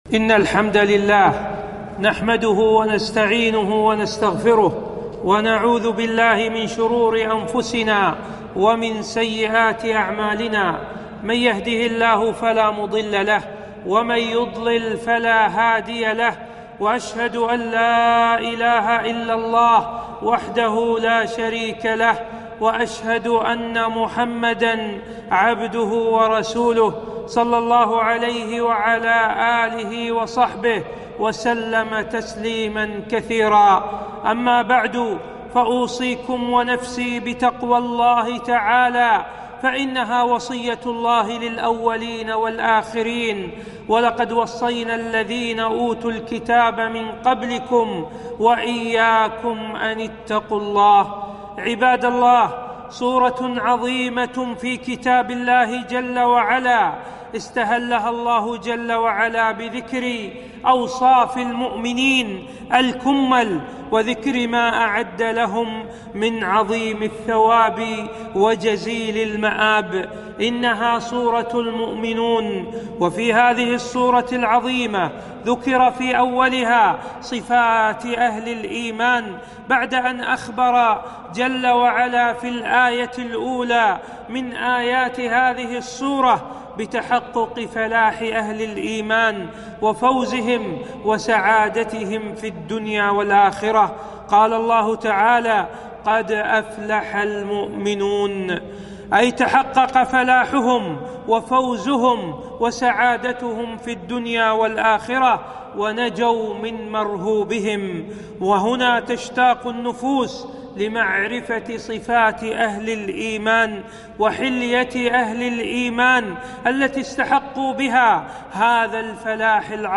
خطبة - صفات المؤمنين المفلحين